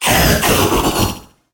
Cri de Zarude dans Pokémon HOME.